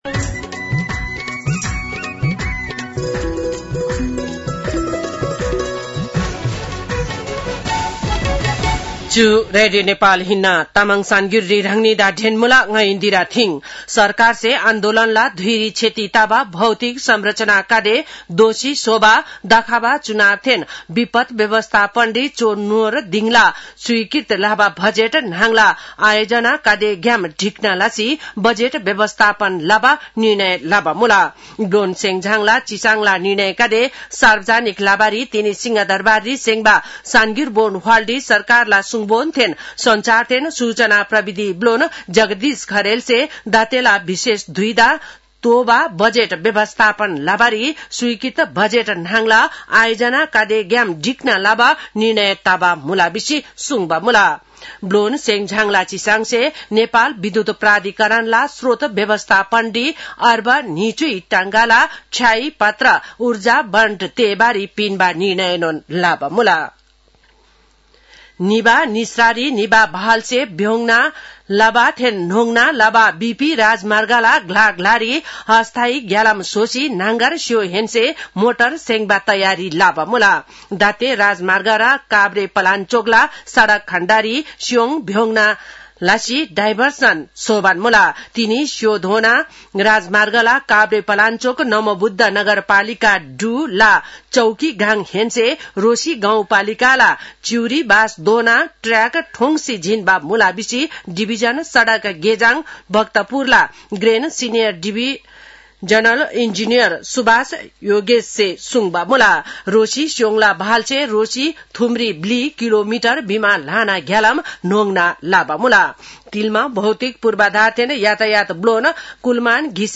तामाङ भाषाको समाचार : २४ असोज , २०८२